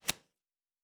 pgs/Assets/Audio/Fantasy Interface Sounds/Cards Place 04.wav
Cards Place 04.wav